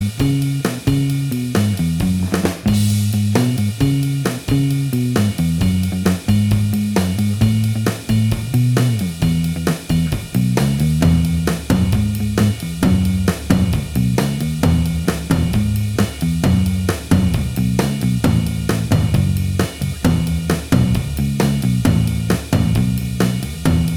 Minus Lead Guitar Pop (1960s) 1:57 Buy £1.50